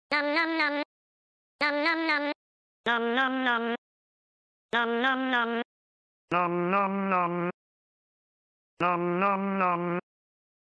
text to speech robot